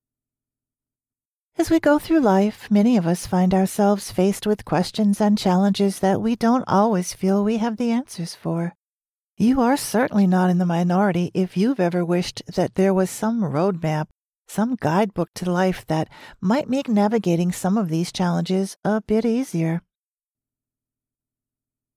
Female
Audiobooks
Non-Fiction
Words that describe my voice are Believable, friendly, approachable.